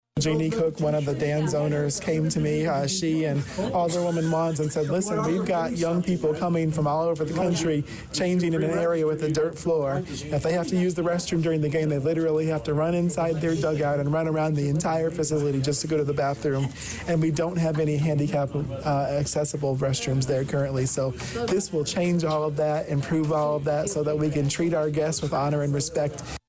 Also forwarded to the full City Council during the Tuesday (September 10th) evening Public Works Committee meeting was the acceptance of a $100,000 grant from the Illinois Department of Commerce and Economic Opportunity (DCEO) for improvements to Danville Stadium; a new visitors locker room and improved rest room facilities.  Mayor Rickey Williams, Jr says he sees the city kicking in about $60,000 to complete a couple of major projects by next year’s Danville Dans season.